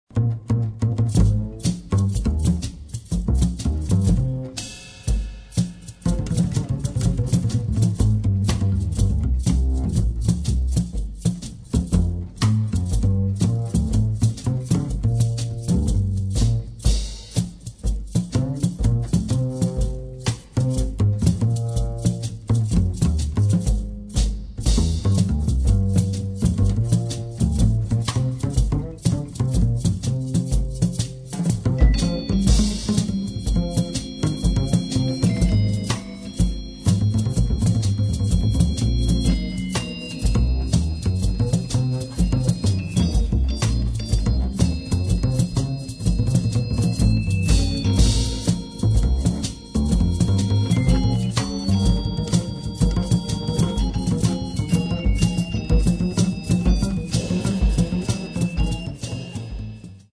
[ JAZZ ]